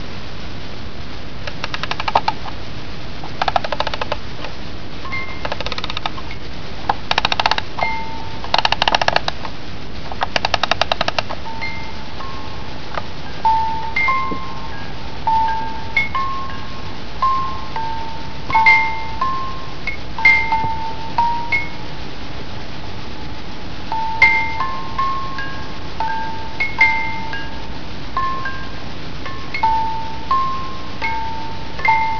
Registrazioni sonore di happening Fluxus